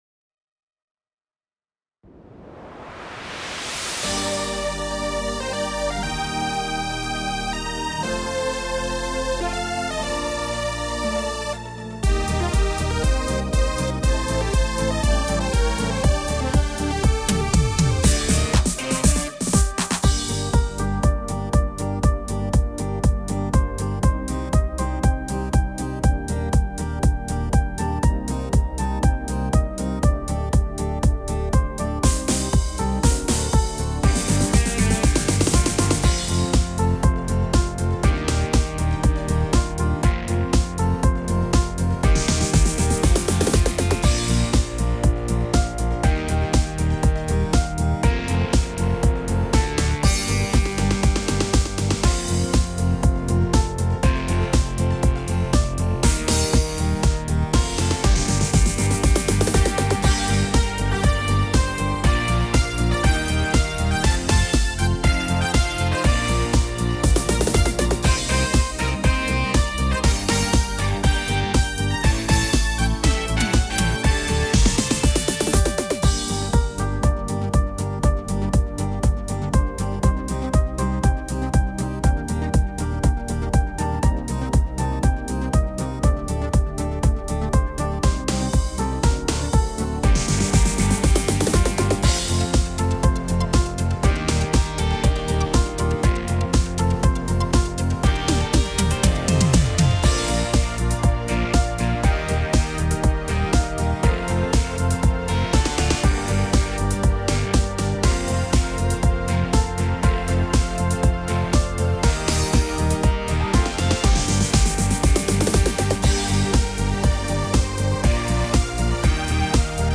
Tone Tốp (Gm)
•   Beat  02.